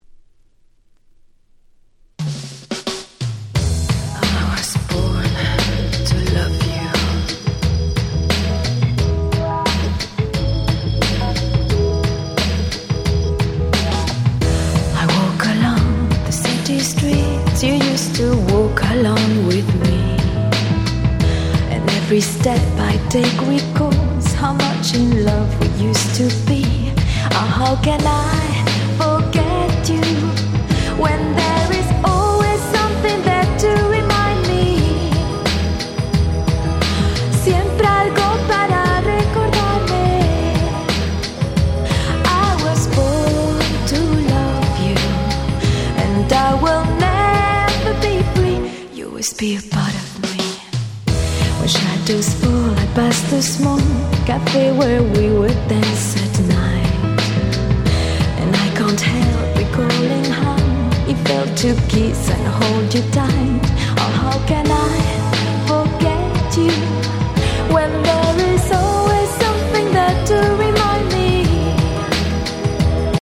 UK出身の美人女性シンガーでシングルは93年からリリースしておりました。
Crossoverな層に受けそうな非常にオシャレな楽曲ばかりです！
Bossa